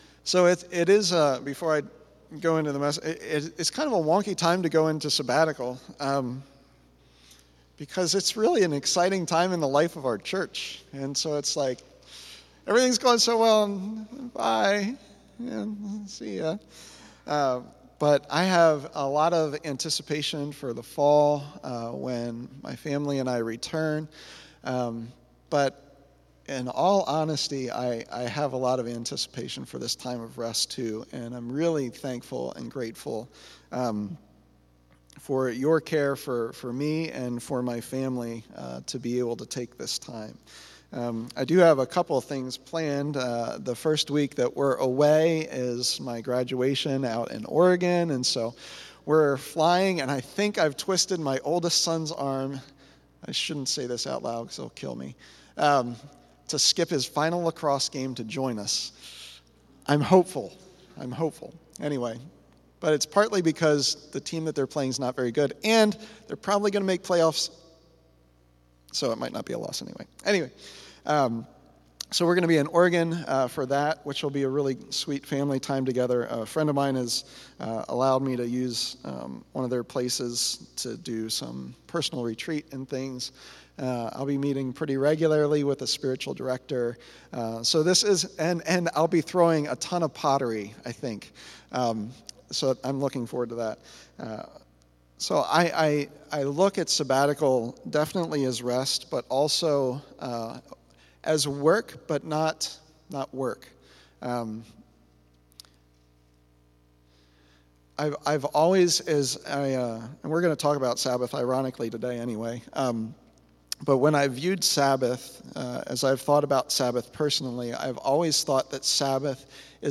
A message from the series "Eastertide."